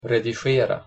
redigera – (редихуэра, редишуэра) редактировать